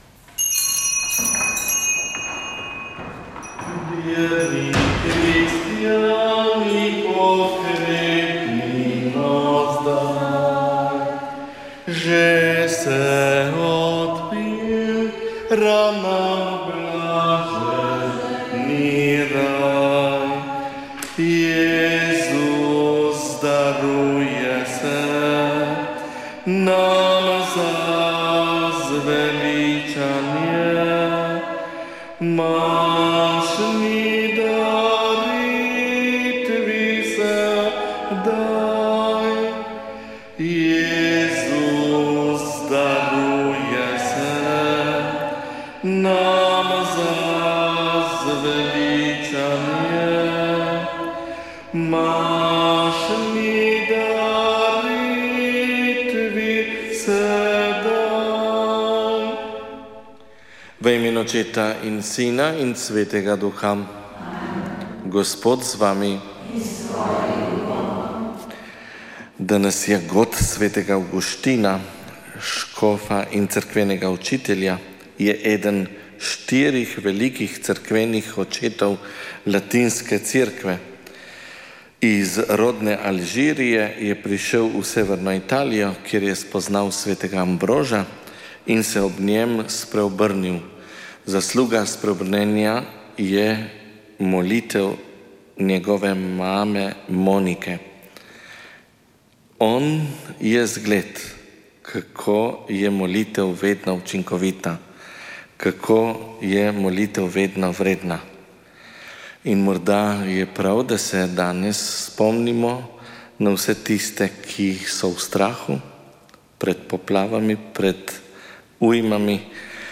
Sveta maša
Sv. maša iz cerkve sv. Marka na Markovcu v Kopru 28. 8.